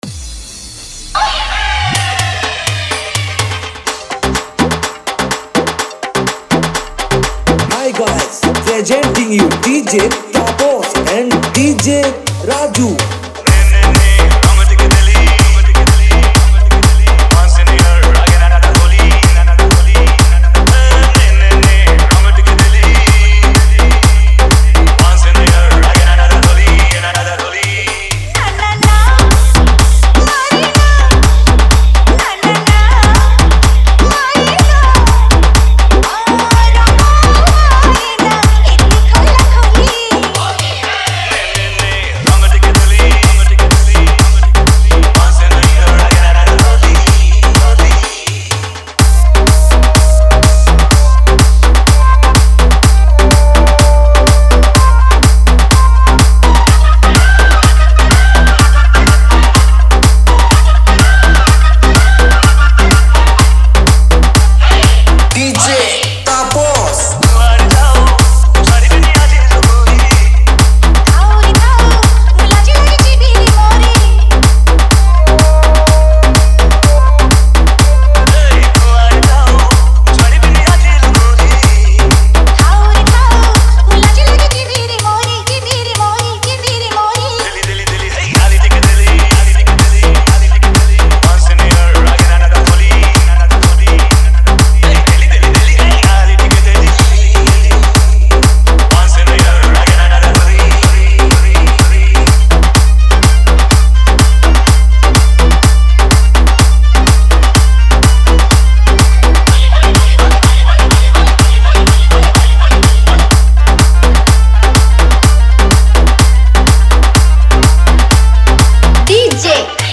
HOLI SPECIAL DJ SONG Songs Download